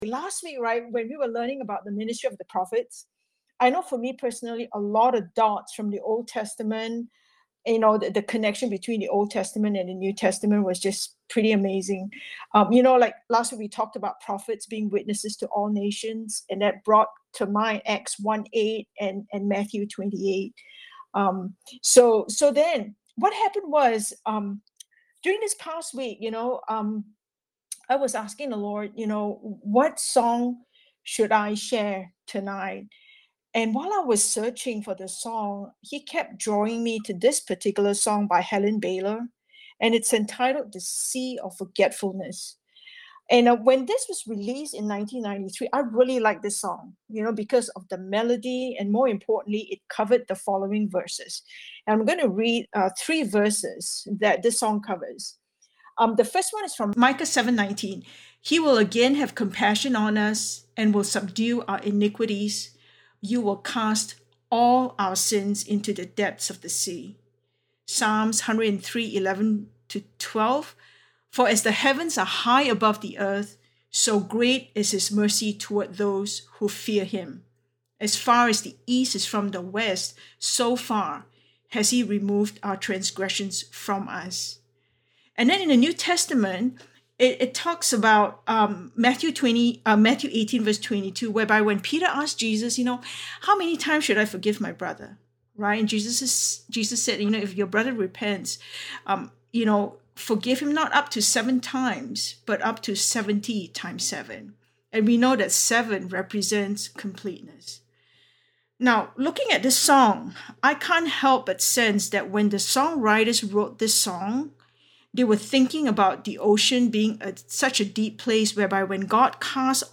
A message from the series "Spiritual Body Building."